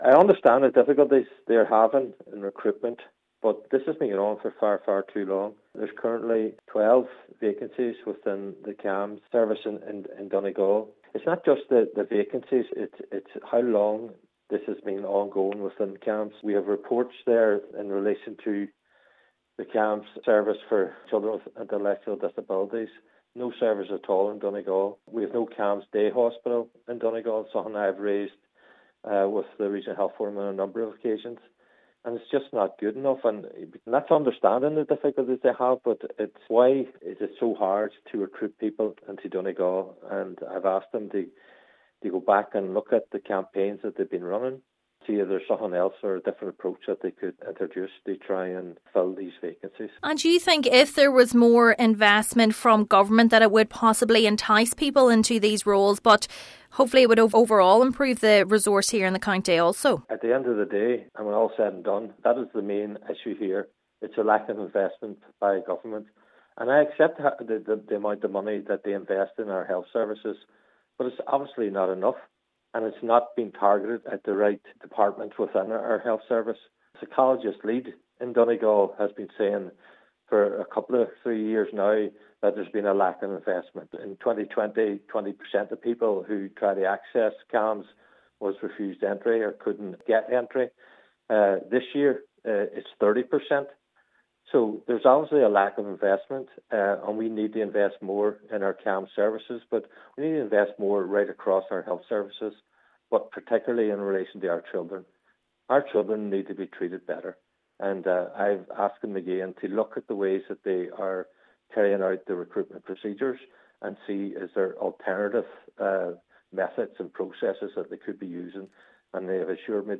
He says it all boils down to a lack of investment in the health service: